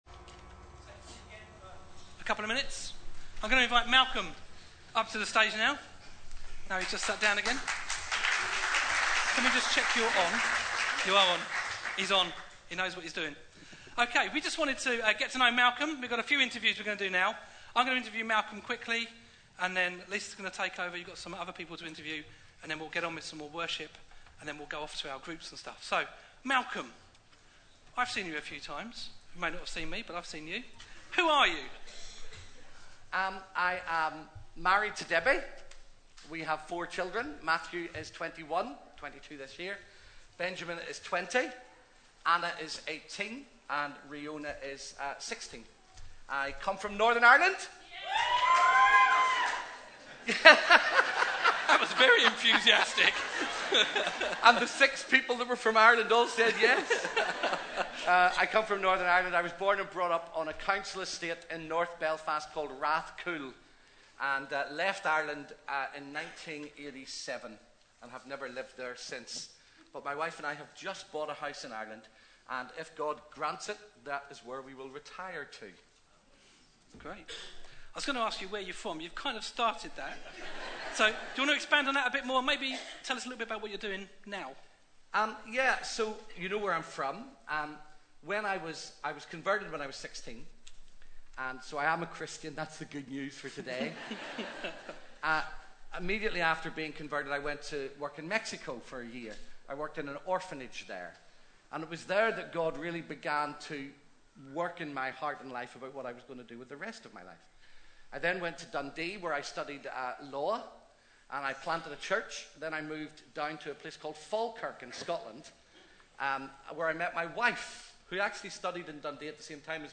A sermon preached on 23rd January, 2016, as part of our Big Day In series.